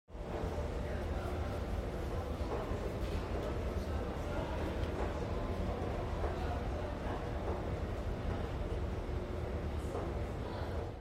دانلود آهنگ پله برقی از افکت صوتی طبیعت و محیط
جلوه های صوتی
دانلود صدای پله برقی از ساعد نیوز با لینک مستقیم و کیفیت بالا